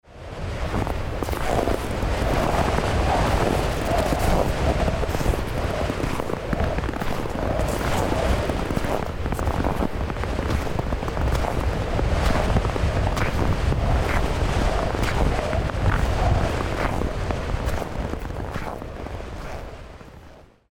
Karty aktywności - skrzypienie śniegu pod nogami - EDURANGA
ka1_k_31_skrzypienie_sniegu-mp3.mp3